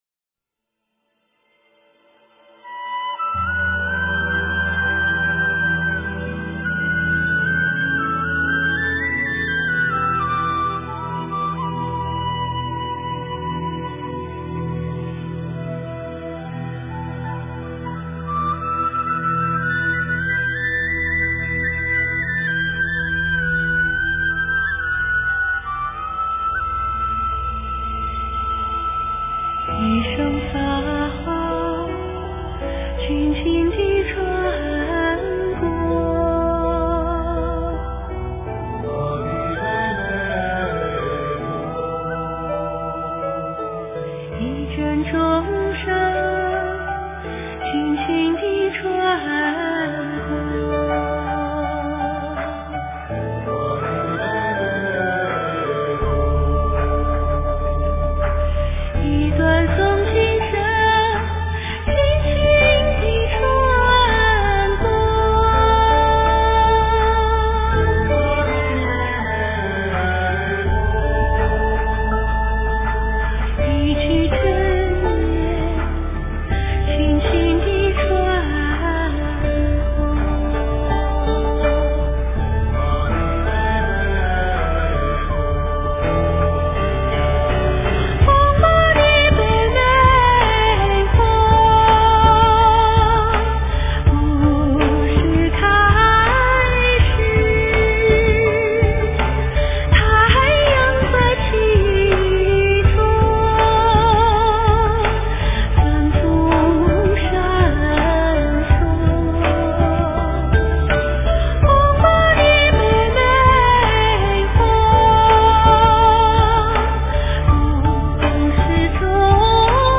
标签: 佛音凡歌佛教音乐